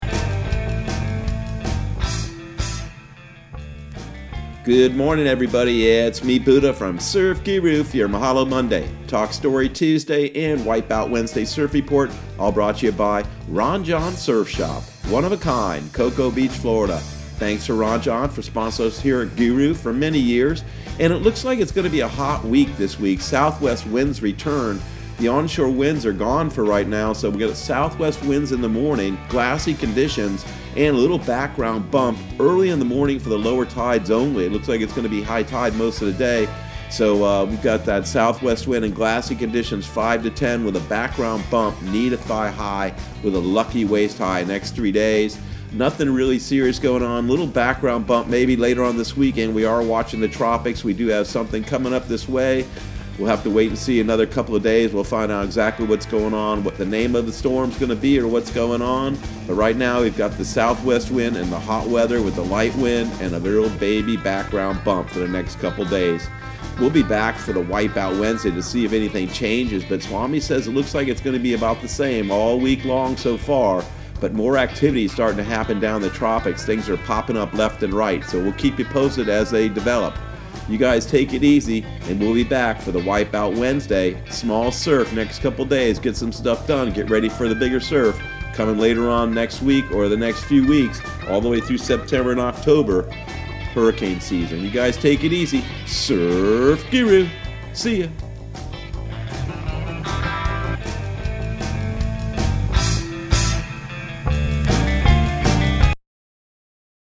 Surf Guru Surf Report and Forecast 07/27/2020 Audio surf report and surf forecast on July 27 for Central Florida and the Southeast.